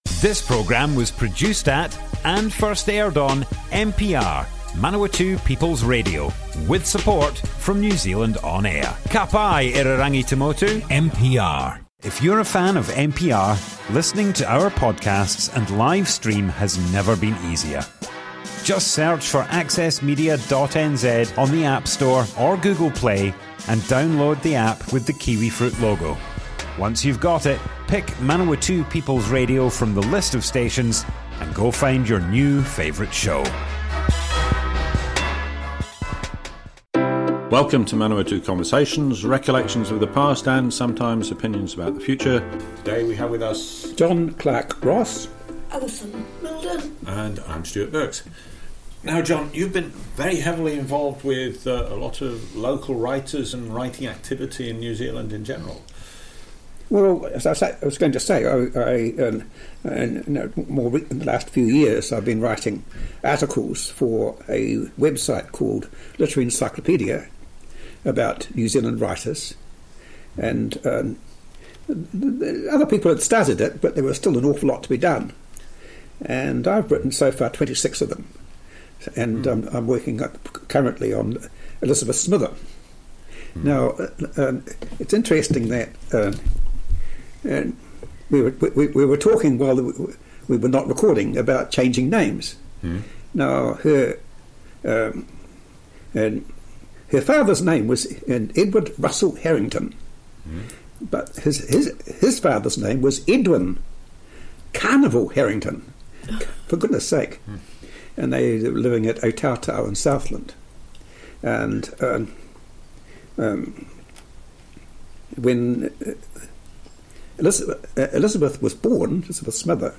Manawatu Conversations Object type Audio More Info → Description Broadcast on Manawatu People's Radio, 24th May 2022.